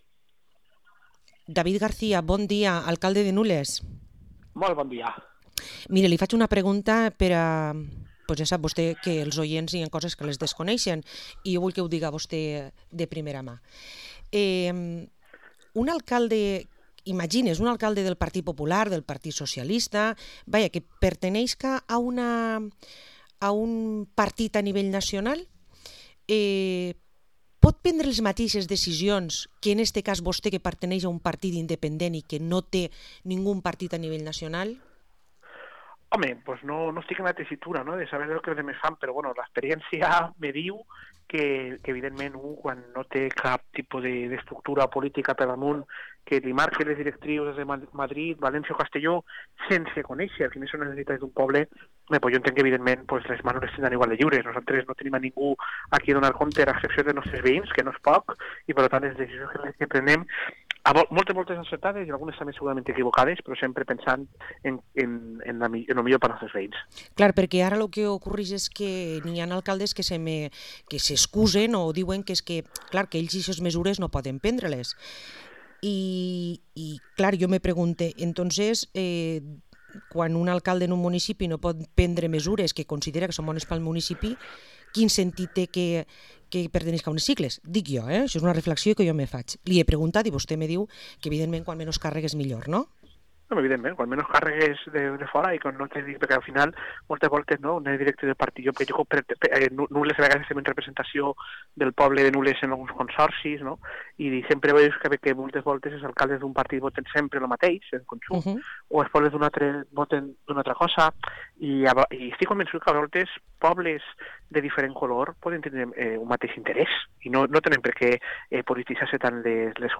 Entrevista a David García, alcalde de Nules